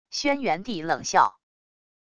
轩辕帝冷笑wav音频